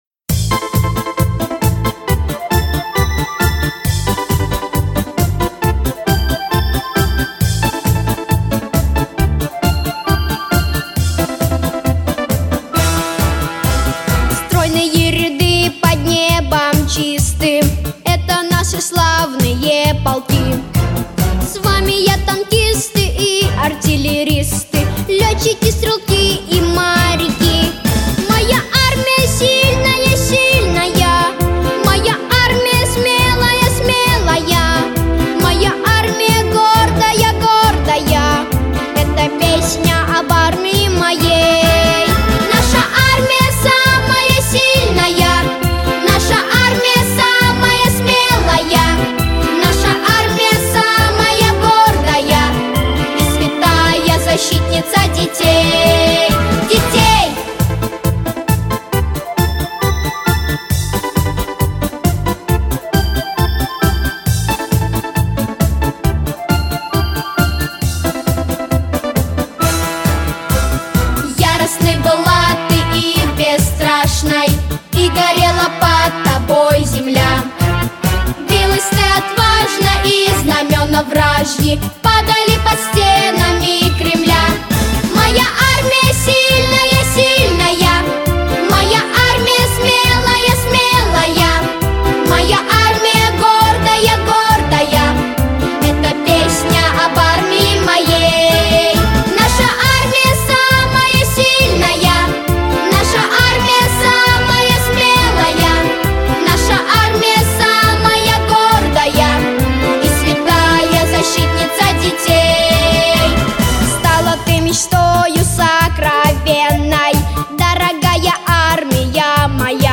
праздничная песня